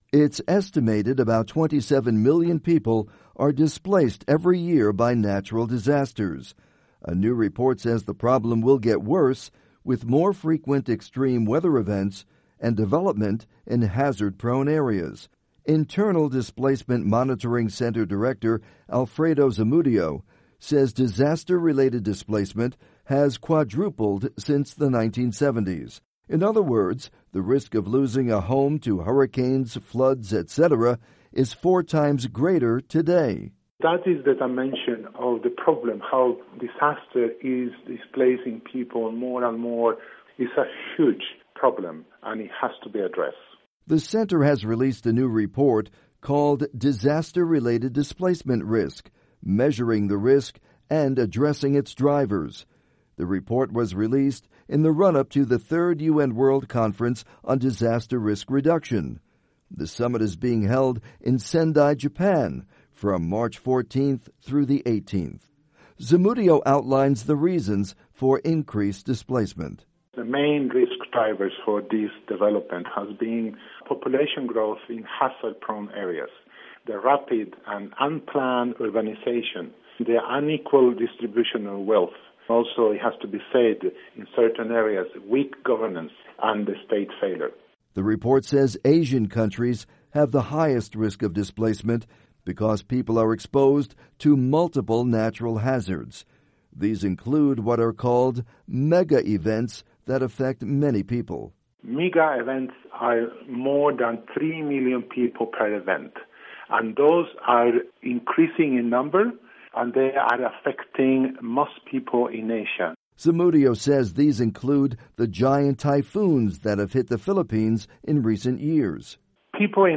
Report: Displacement Risk Soars